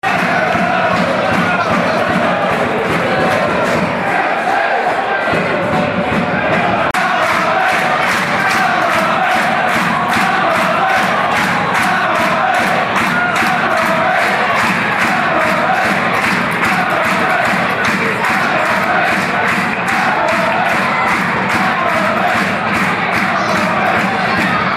Listen to County fans in fine voice today!